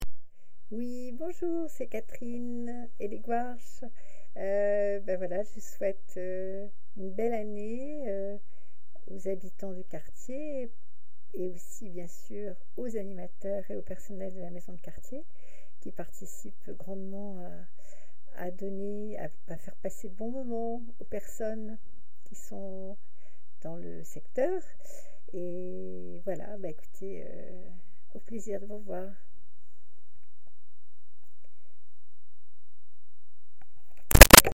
Témoignage enregistré le 27 février 2026 à 11h48